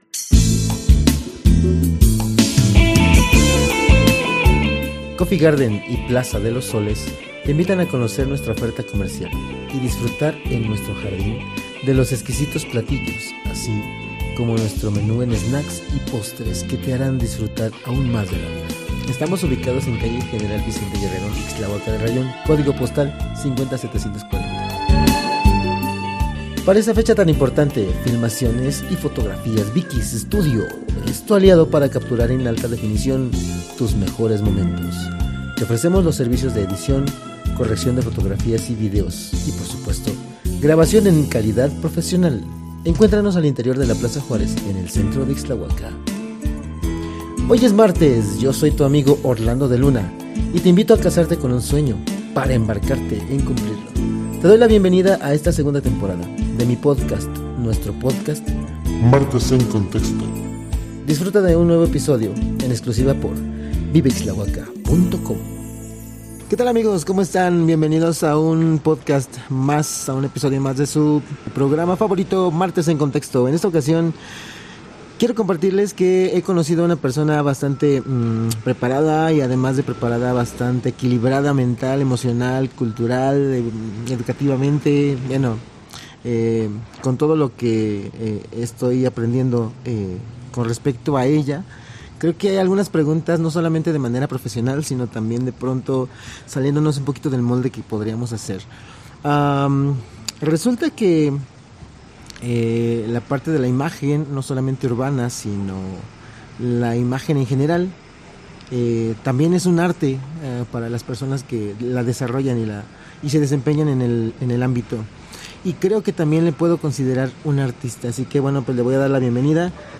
Locación: Cafetería “Coffee Garden".